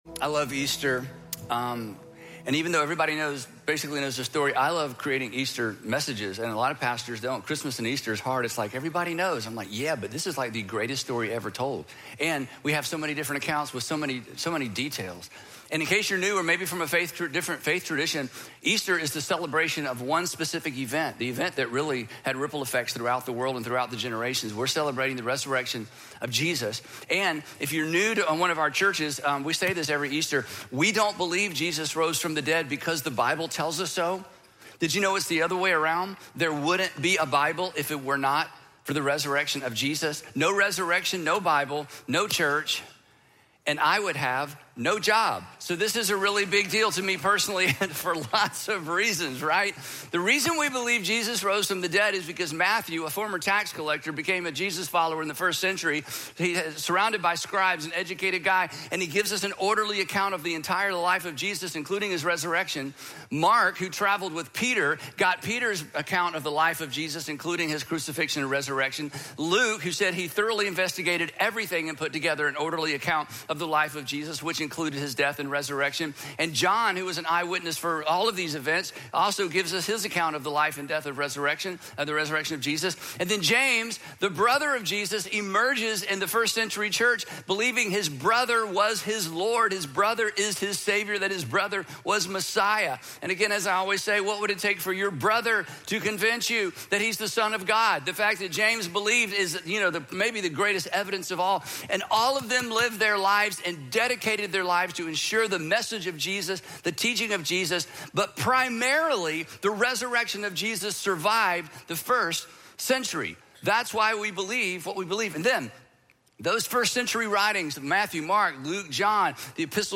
If Jesus really rose from the dead, his resurrection has real implications for your life, your faith, and your future. In this Easter message, we talk about this event, why we celebrate it, and why it is the anchor for everything we believe.